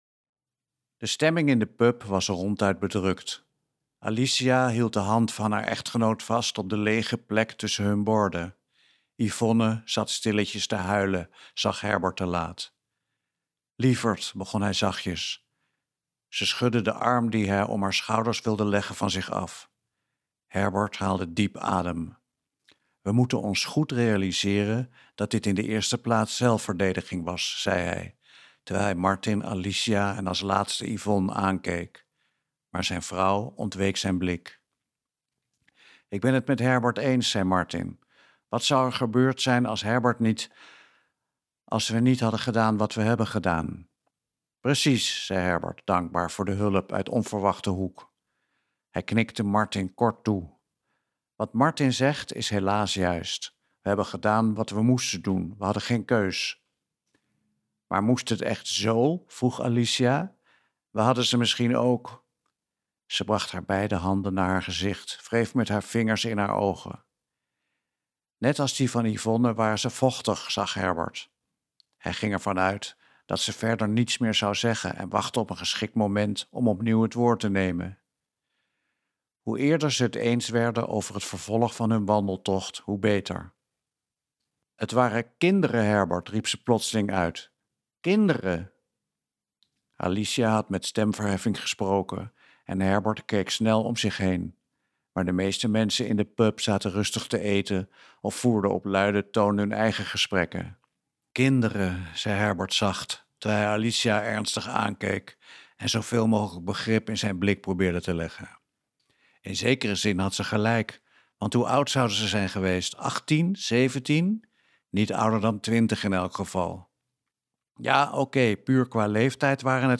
Ambo|Anthos uitgevers - De overbodigen luisterboek